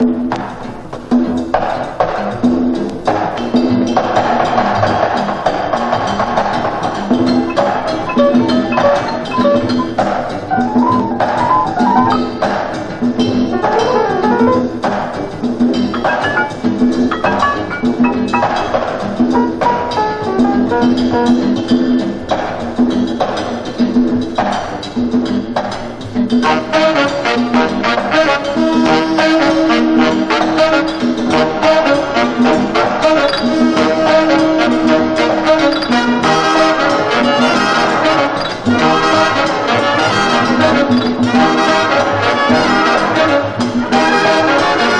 Jazz, Big Band　USA　12inchレコード　33rpm　Mono